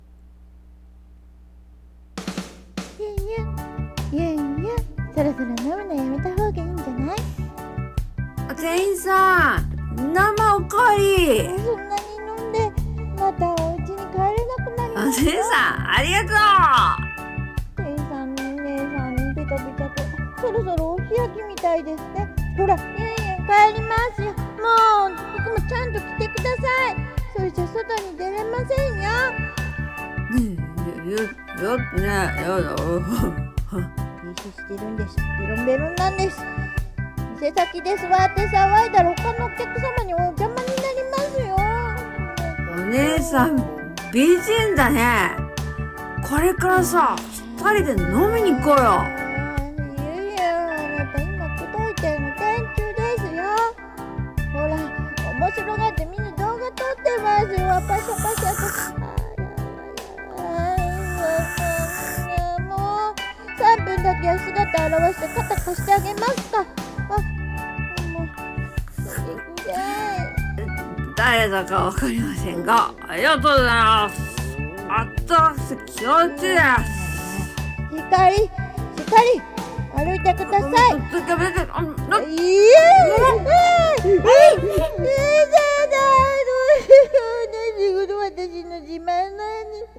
さんの投稿した曲一覧 を表示 2人声劇【ゆんゆんは泥酔】帰るよー💦【天使】